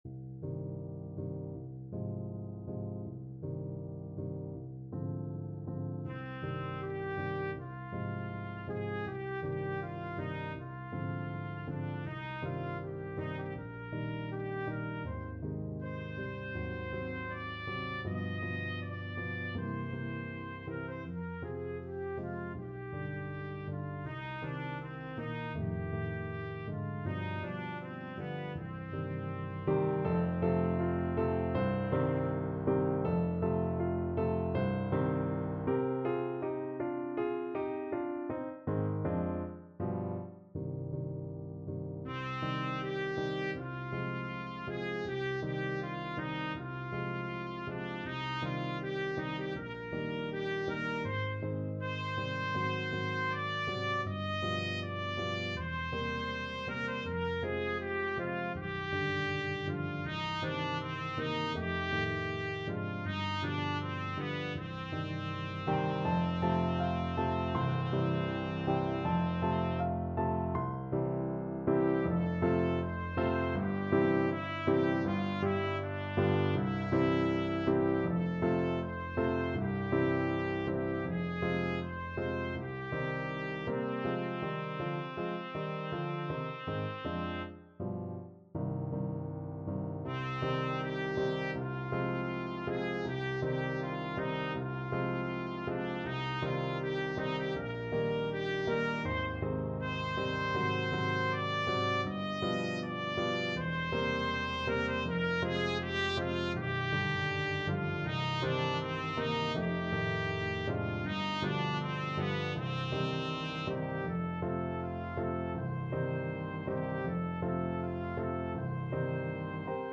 2/4 (View more 2/4 Music)
C minor (Sounding Pitch) D minor (Trumpet in Bb) (View more C minor Music for Trumpet )
~ = 100 Andante
Trumpet  (View more Intermediate Trumpet Music)
Classical (View more Classical Trumpet Music)
jarnefelt_berceuse_TPT.mp3